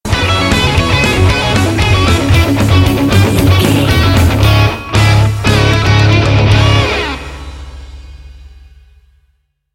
Aeolian/Minor
Fast
foreboding
aggressive
intense
driving
dark
heavy
bass guitar
electric guitar
drum machine